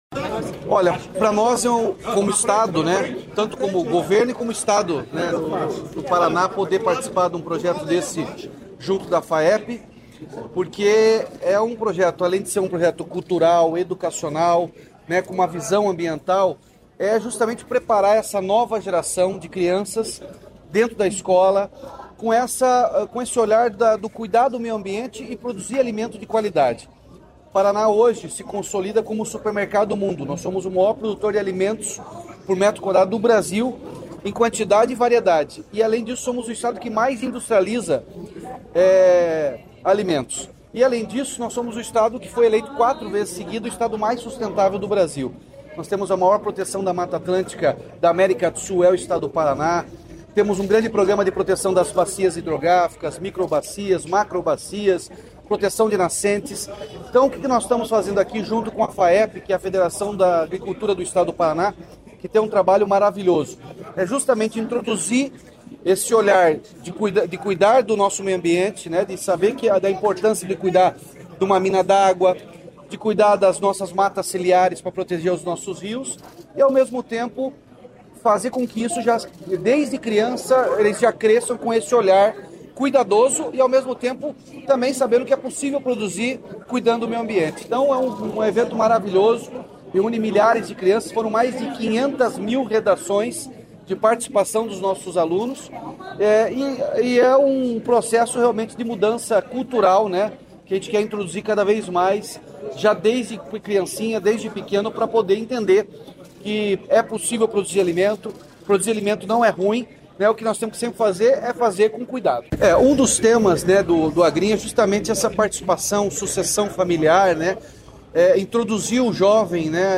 Sonora do governador Ratinho Junior sobre a premiação do Concurso Agrinho 2025 | Governo do Estado do Paraná